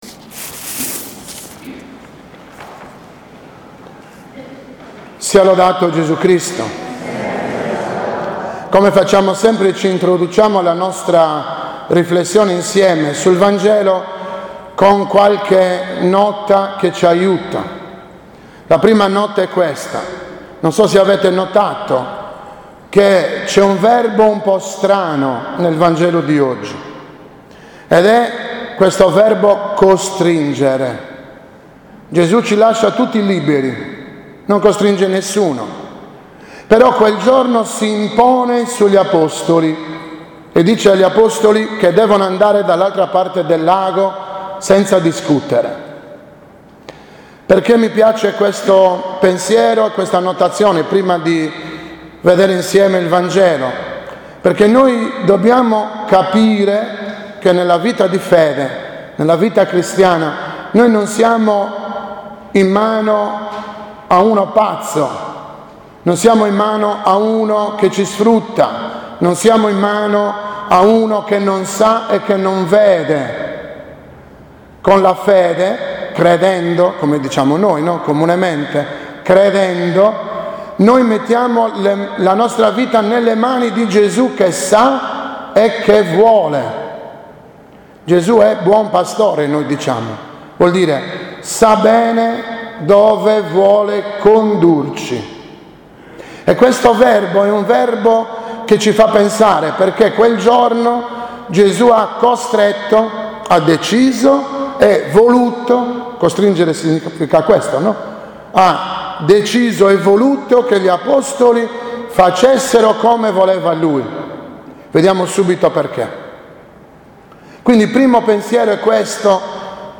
13.8.2017 OMELIA della XIX DOMENICA DEL TEMPO ORDINARIO A